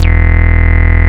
74.02 BASS.wav